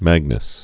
(măgnəs)